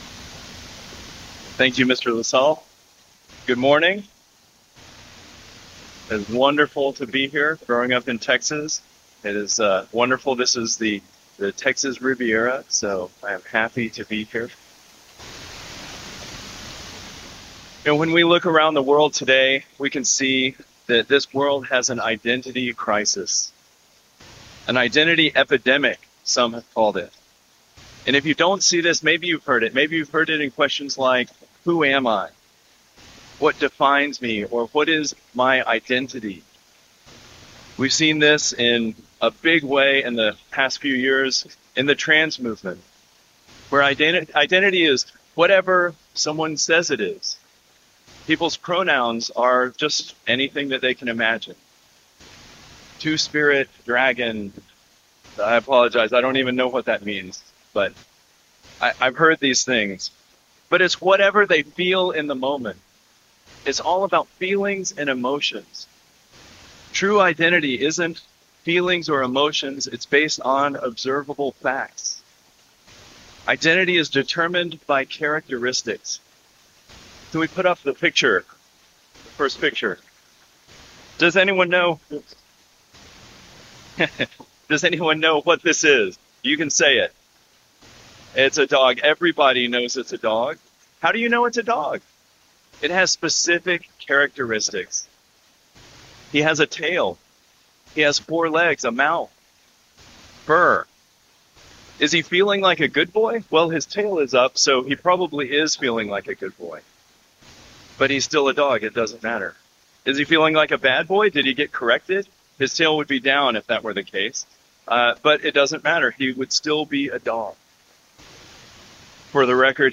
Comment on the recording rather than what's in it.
Given in Aransas Pass, Texas